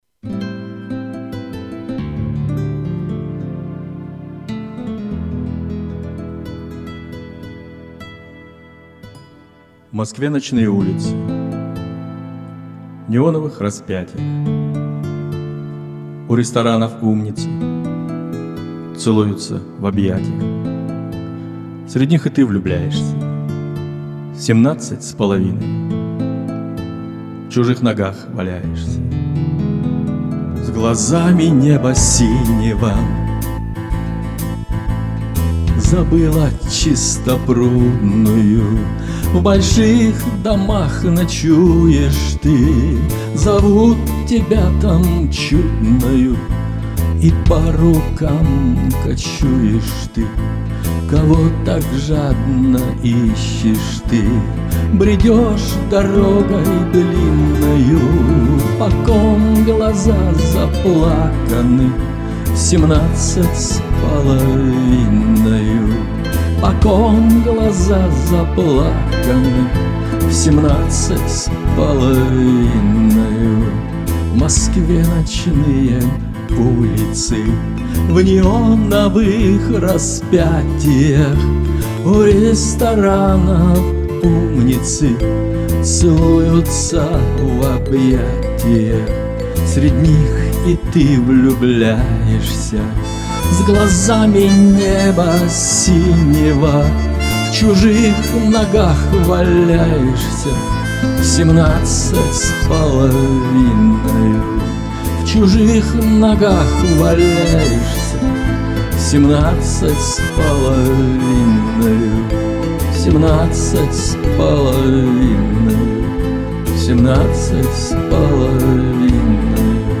исполнение (самодеятельное)